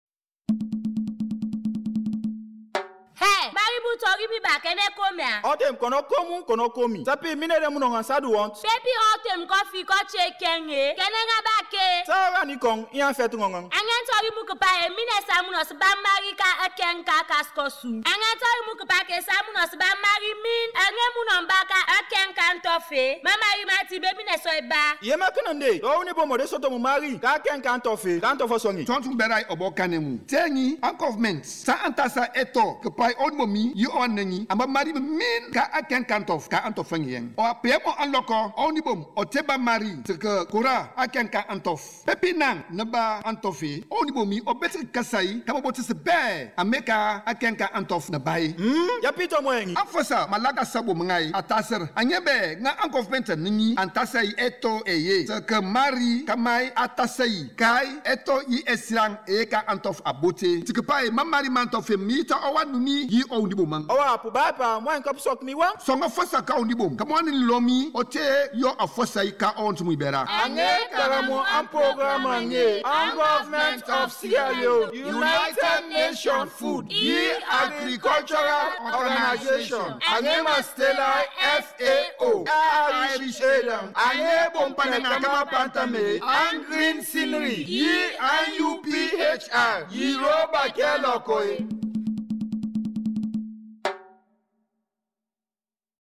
Temne jingle